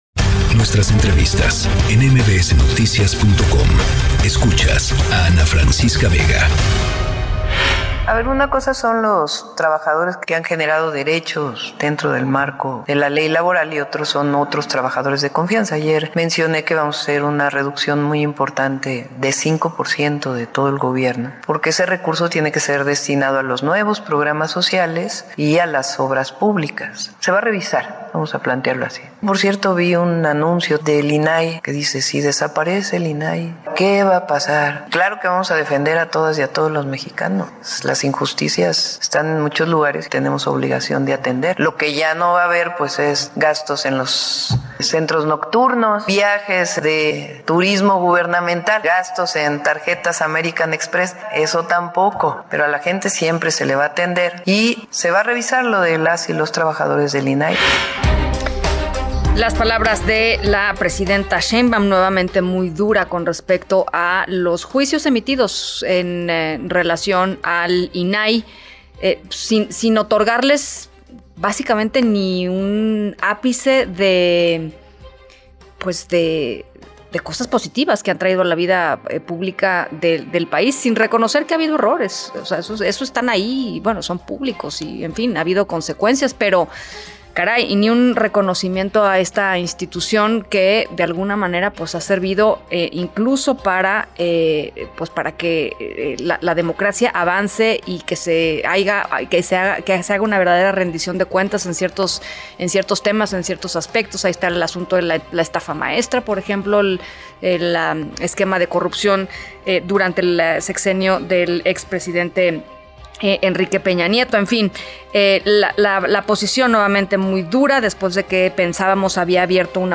Entrevista con Ana Francisca Vega - julietadelrio
Platico con Ana Francisca Vega en MVS Noticias sobre el INAI y las próximas reuniones que tendremos en el Senado y la Cámara de Diputados.